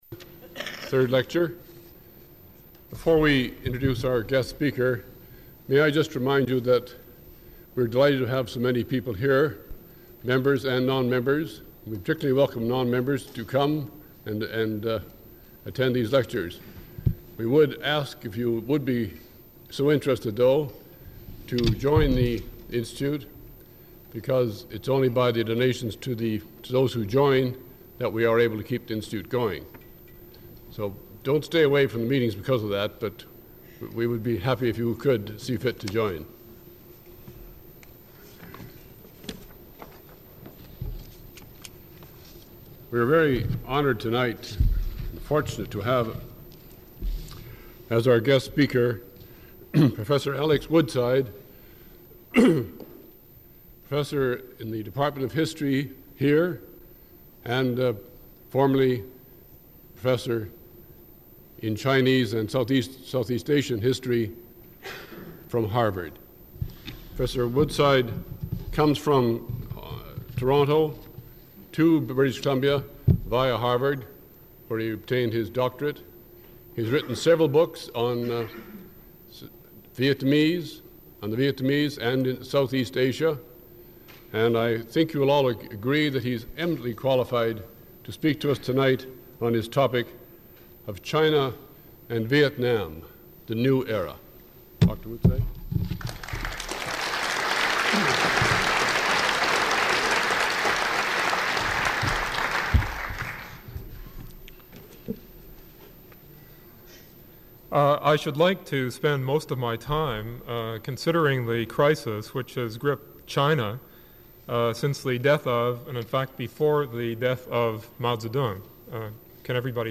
Original audio recording available in the University Archives (UBC AT 460:1/2).